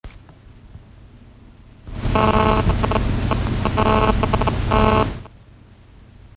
Электронный писк из мониторов
Всем добрый день! Столкнулся с проблемой, после переезда за город мониторы начали периодически издавать "электронный" писк (файл с этим писком прикрепляю).
Вся звуковая аппаратура (мониторы и звуковая карта) подключена через удлинитель без заземления и розетку на другой стороне комнаты.
Помимо этого писка никаких других посторонних шумов мониторы не выдают.